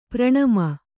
♪ praṇama